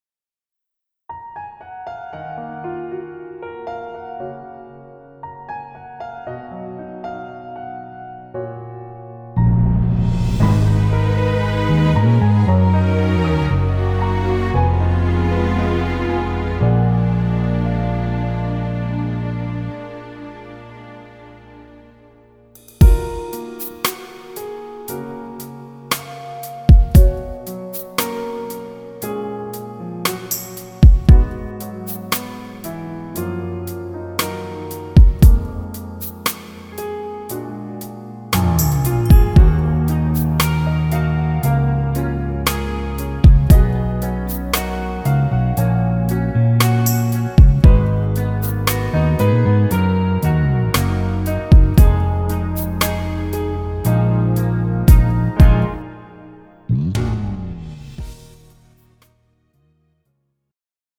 장르 가요 구분